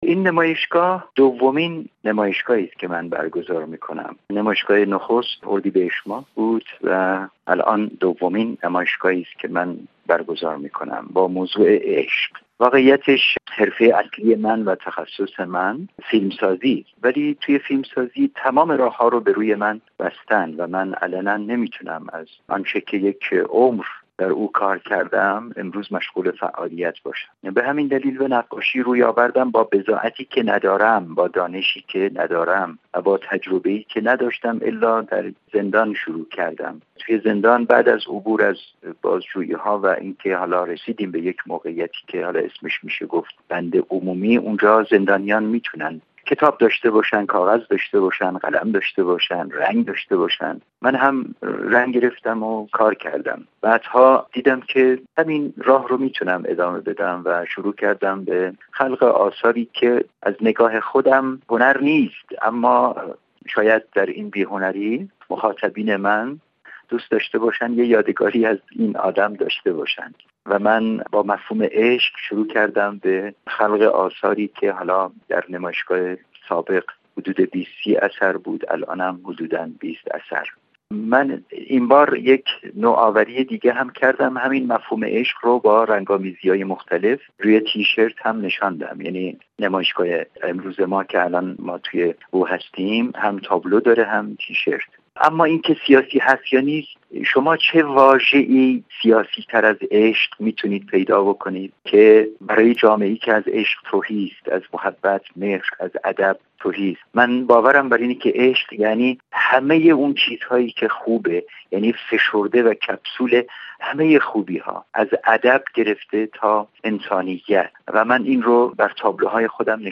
نوری‌زاد در گفت‌وگو با بخش فارسی رادیو بین المللی فرانسه می گوید که عشق برای جامعه ای که از عشق تهی است، سیاسی‌ترین واژه است.
توضیح محمد نوری زاد، به بخش فارسی رادیو بین المللی فرانسه